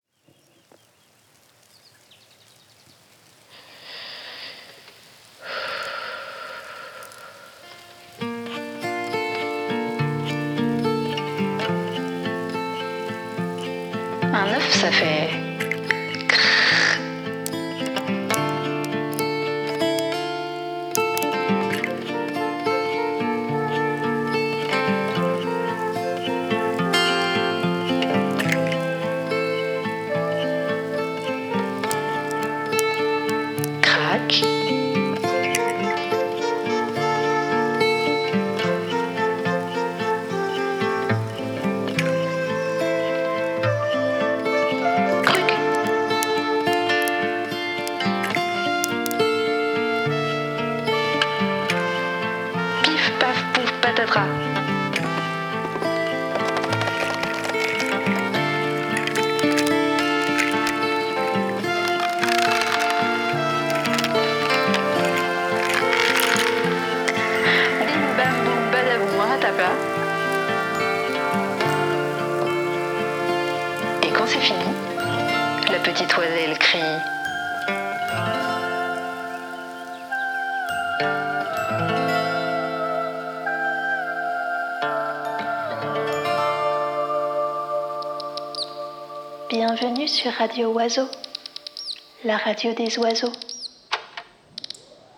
Enregistrement  : La Cabine Rouge, Paris